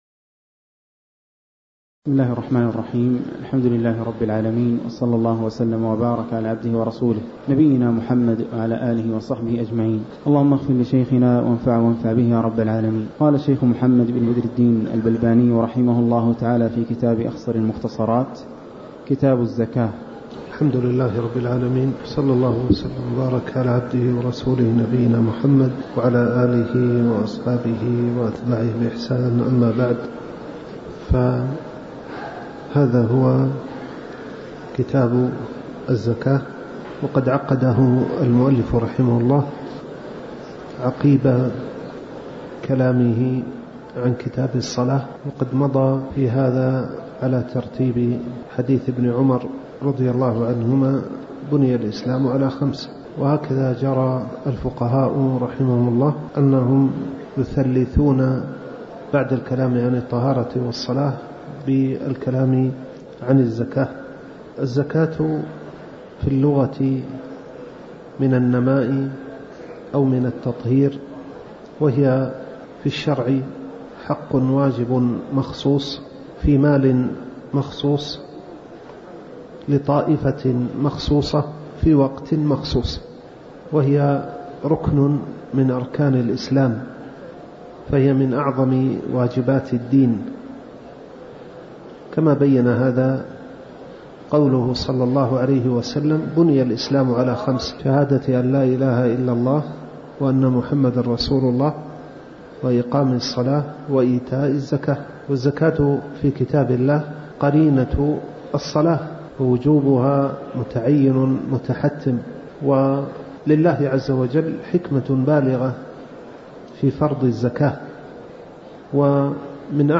تاريخ النشر ١ محرم ١٤٤٠ هـ المكان: المسجد النبوي الشيخ